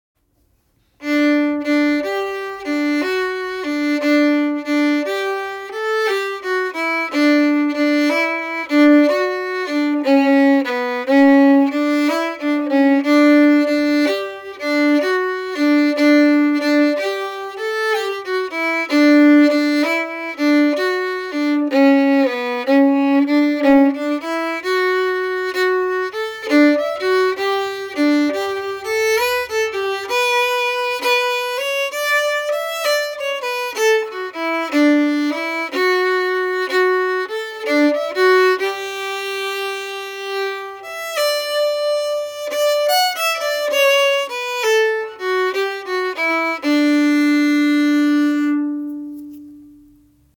The Snowy Path harmony slow 2 (MP3)Download
the-snowy-path-harmony-slow-2.mp3